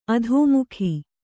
♪ adhōmukhi